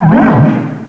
pokeemerald / sound / direct_sound_samples / cries / throh.aif